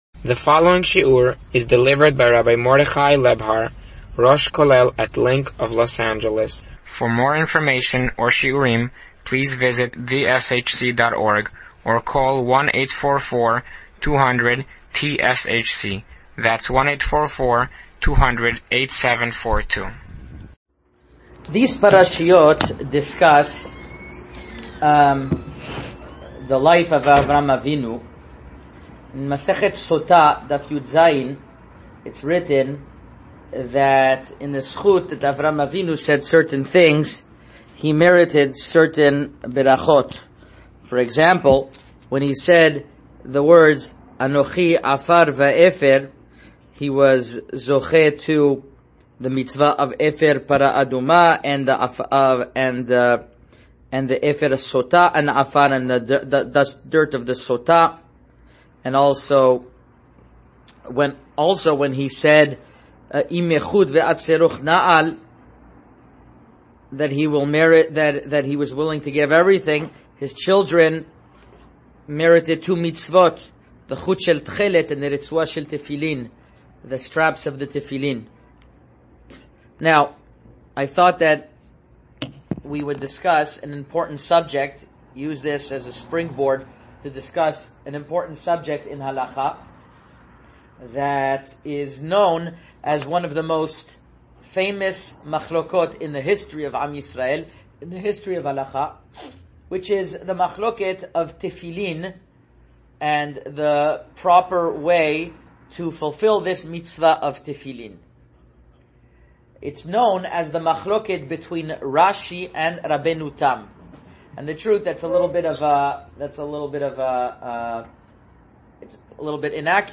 A Parasha & Halacha Shiur